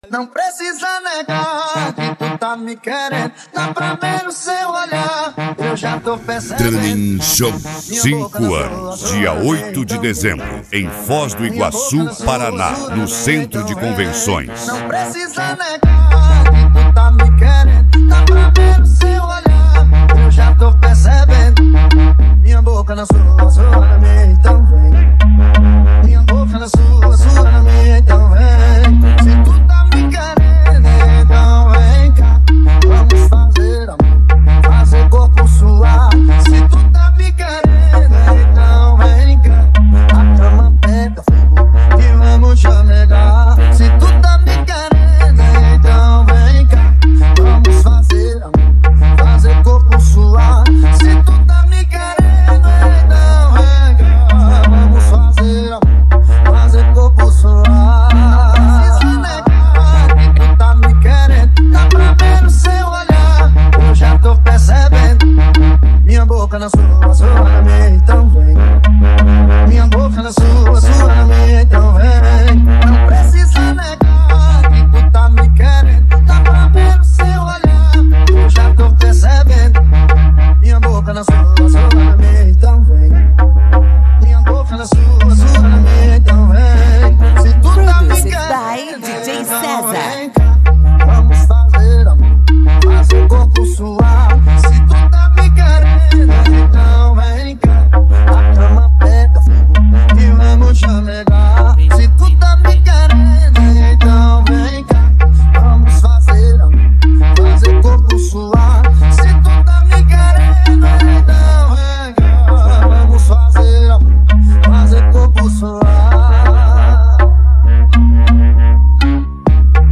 Mega Funk